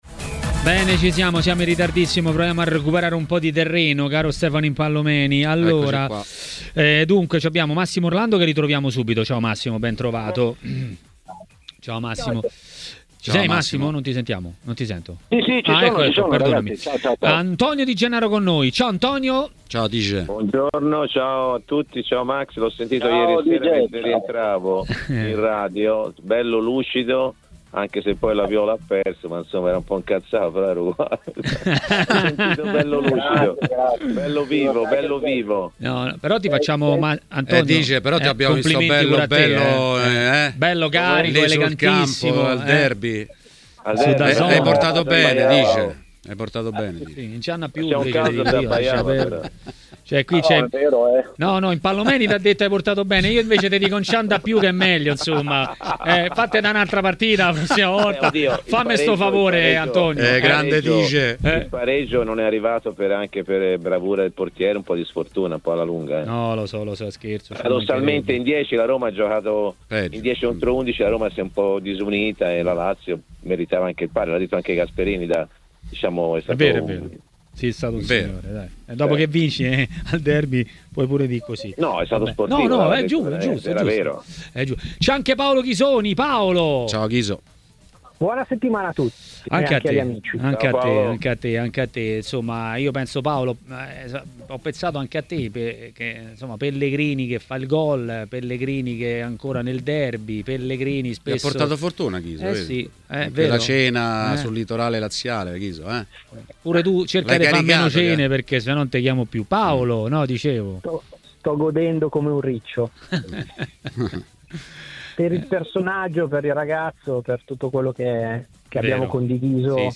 L'ex calciatore e commentatore tv Antonio Di Gennaro ha parlato dei temi del giorno a TMW Radio, durante Maracanà.